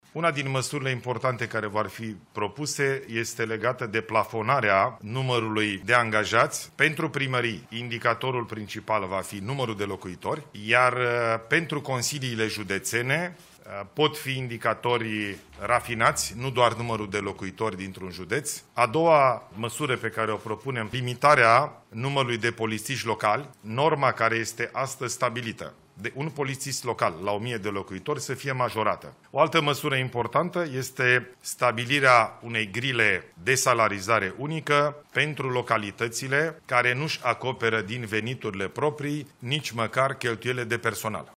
Premierul Ilie Bolojan a anunțat o parte dintre măsurile bugetar-fiscale din pachetul al doilea propus de Guvern.
18iul-19-Ilie-Bolojan-despre-masuri-in-administratie.mp3